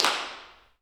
OAK_clap_mpc_03.wav